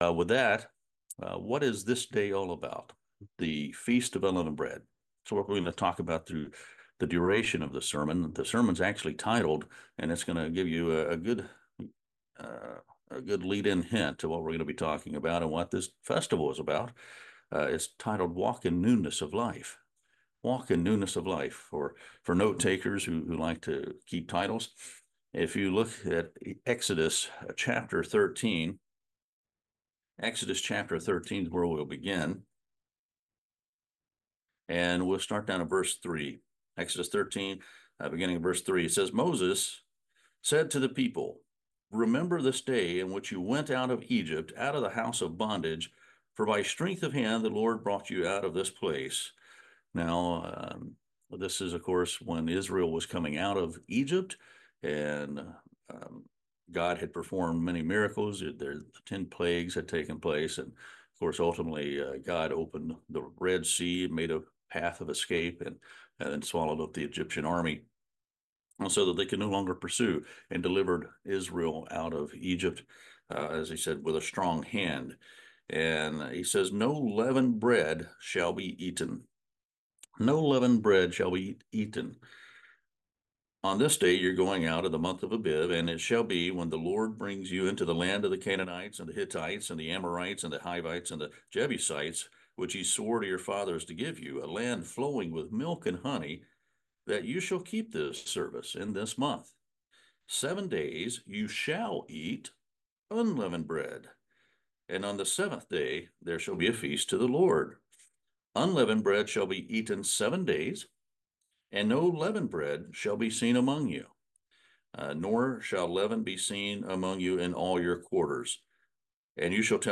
Service Type: Sermon